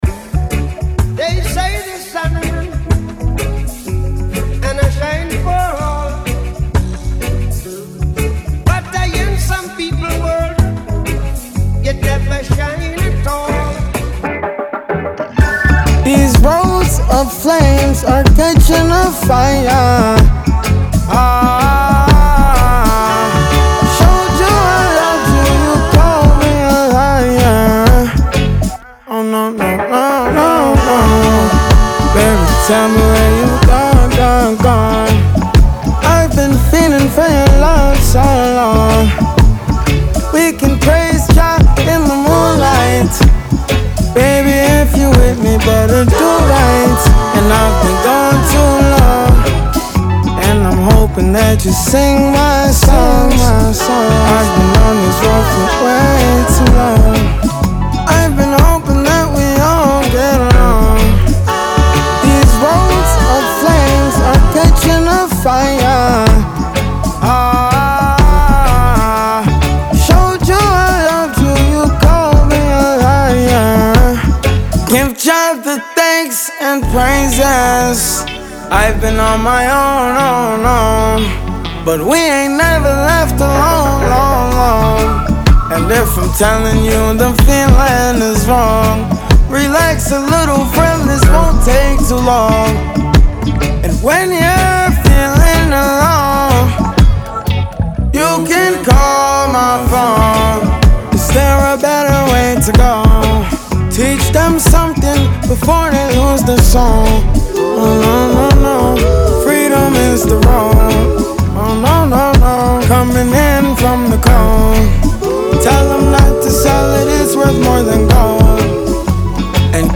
• Жанр: Reggae